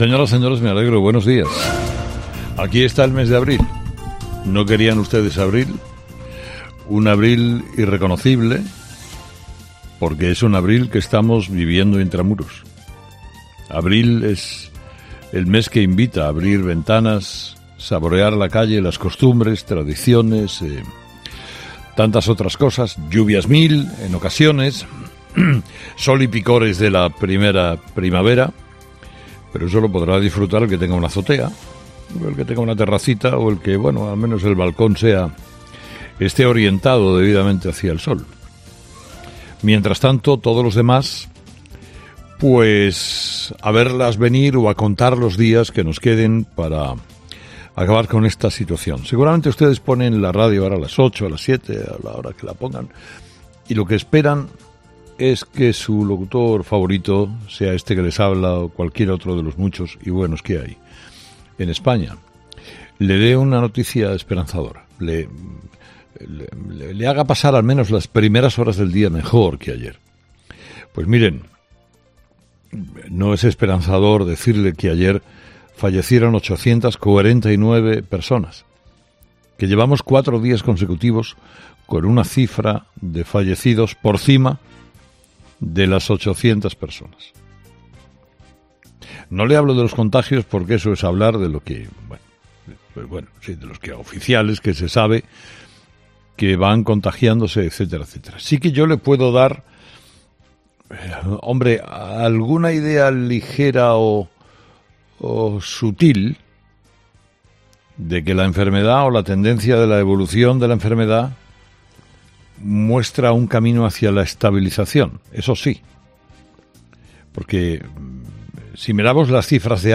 El director de 'Herrera en COPE', Carlos Herrera, analiza las medidas aprobadas por el vicepresidente segundo, Pablo Iglesias